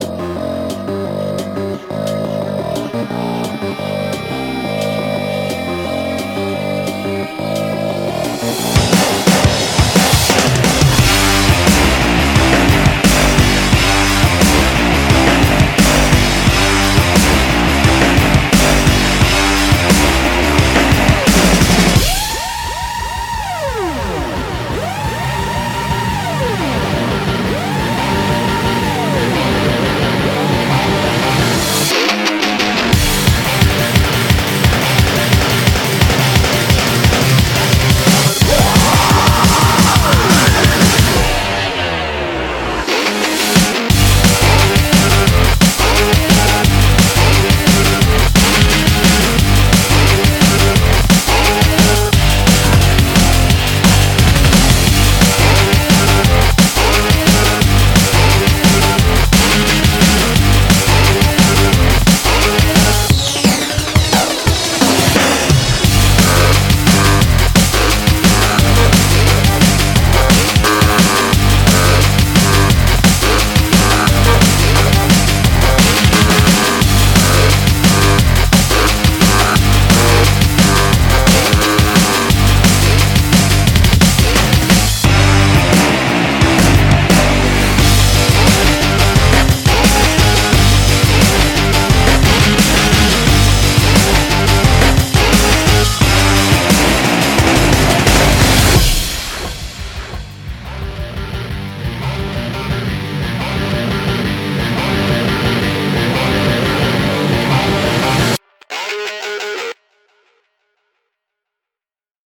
BPM175
MP3 QualityMusic Cut